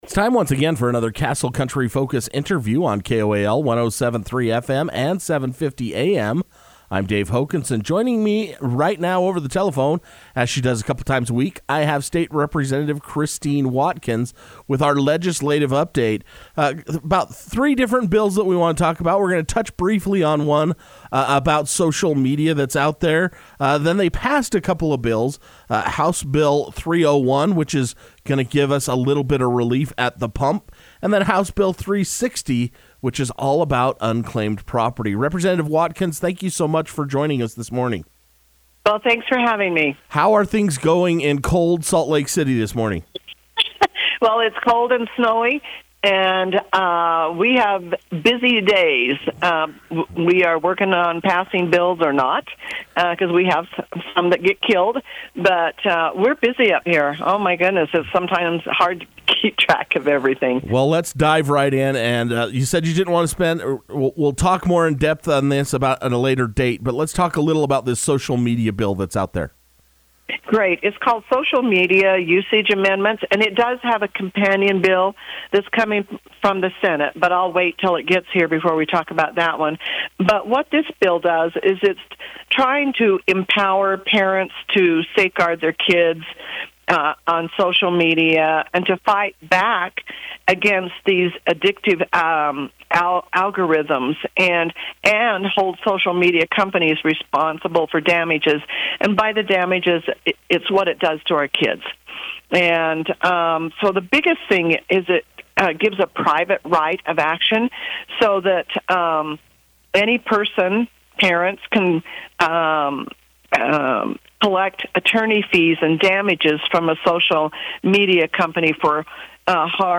Castle Country Radio is conducting weekly Legislative updates with Representative Christine F. Watkins while the general session is taking place this year. She will give a brief report on the dealings taking place on the hill twice a week, so she took time on Wednesday, Feb. 15 to speak over the telephone.